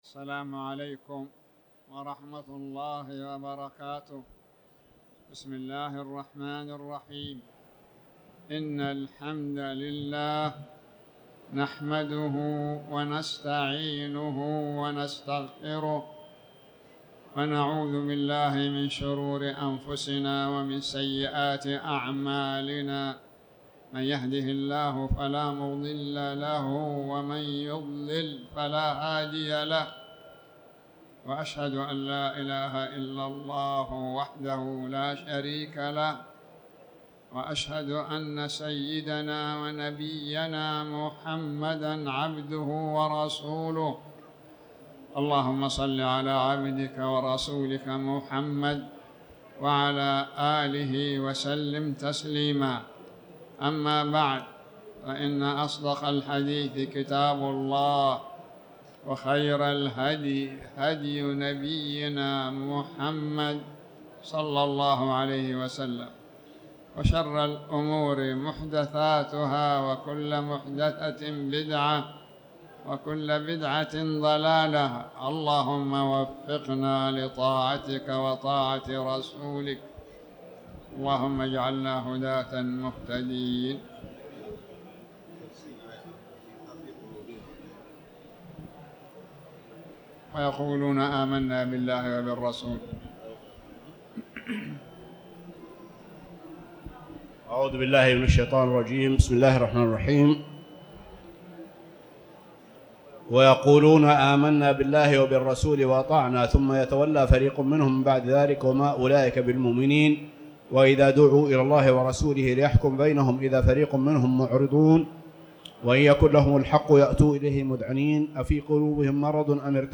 تاريخ النشر ١٧ جمادى الأولى ١٤٤٠ هـ المكان: المسجد الحرام الشيخ